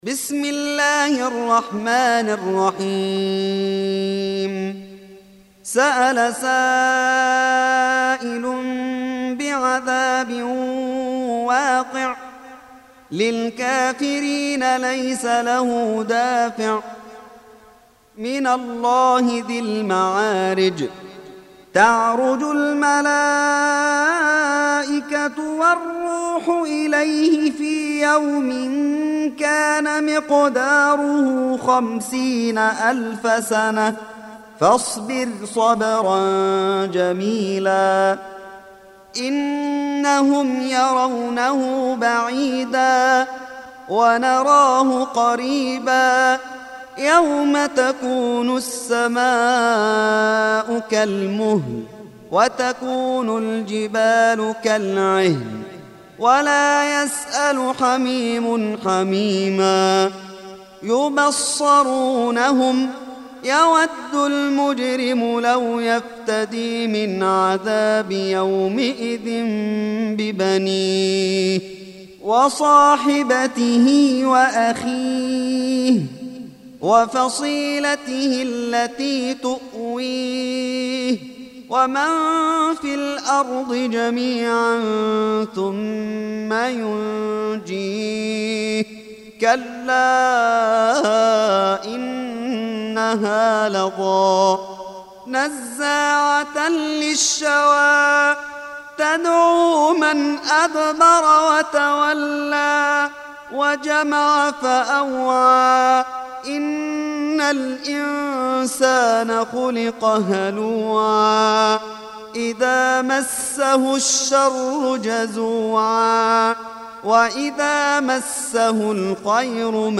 Surah Repeating تكرار السورة Download Surah حمّل السورة Reciting Murattalah Audio for 70. Surah Al-Ma'�rij سورة المعارج N.B *Surah Includes Al-Basmalah Reciters Sequents تتابع التلاوات Reciters Repeats تكرار التلاوات